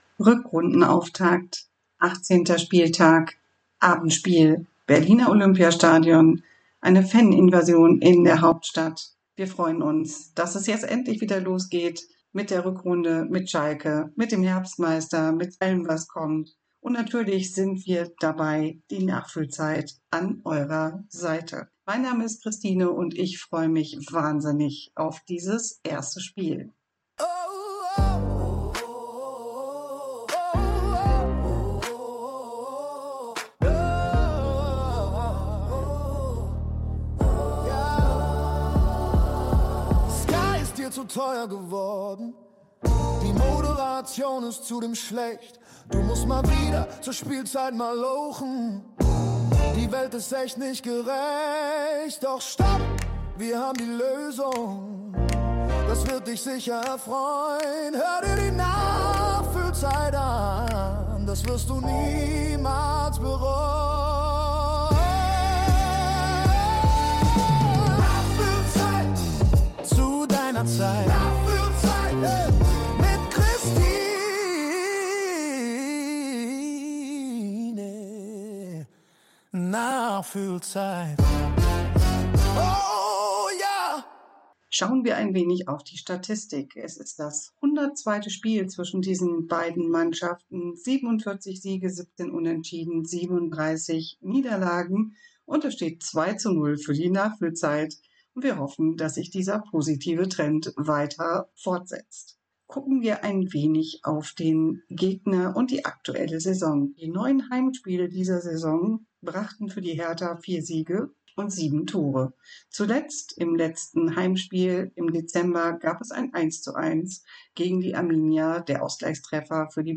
Schalke Audio Re-Live com 18.01.2026